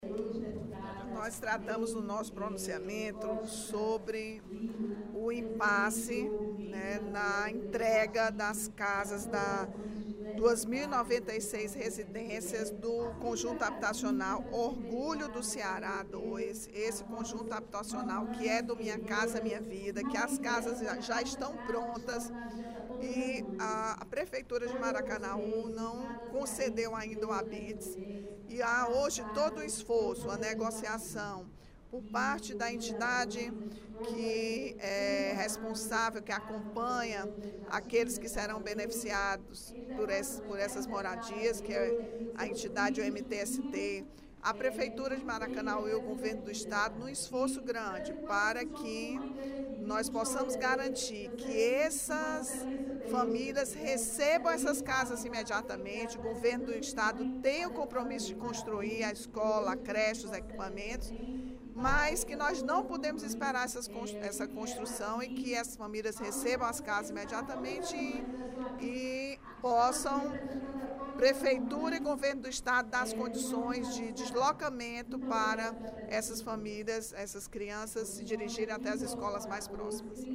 A deputada Rachel Marques (PT) esclareceu, durante pronunciamento no primeiro expediente da sessão plenária desta sexta-feira (12/05), o impasse envolvendo o conjunto habitacional Orgulho do Ceará II.